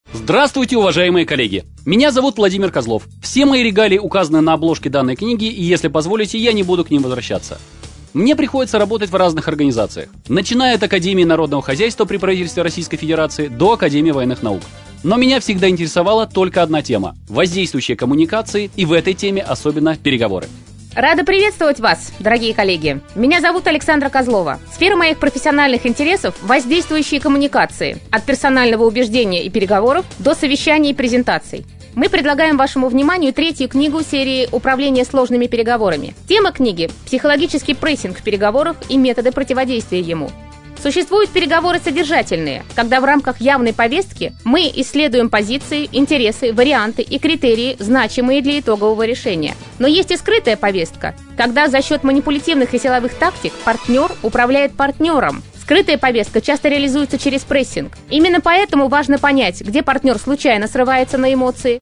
Аудиокнига Психологический прессинг | Библиотека аудиокниг